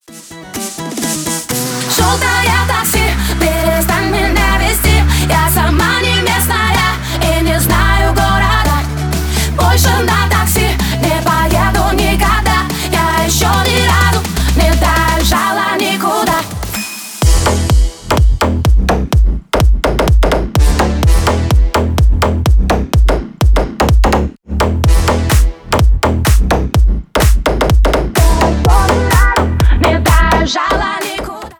клубные , ремиксы